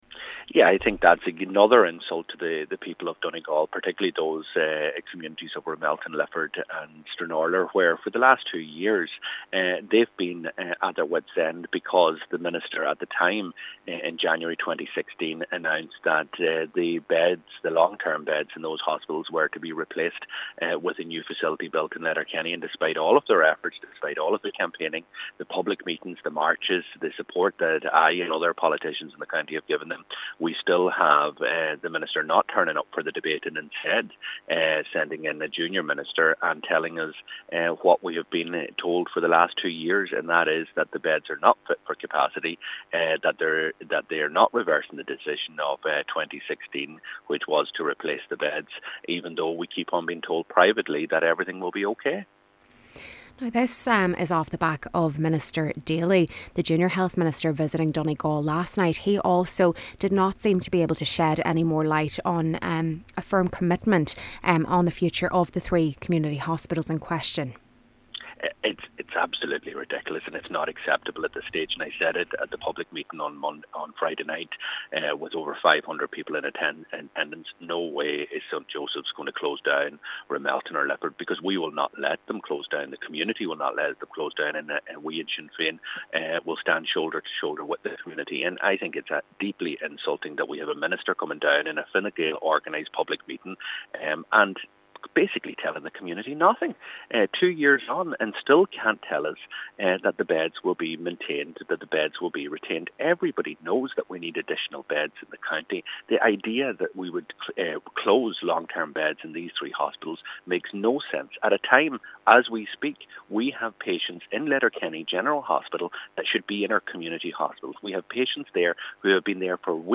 Donegal Deputy Pearse Doherty says the move demonstrated the contempt which this Government has for these communities: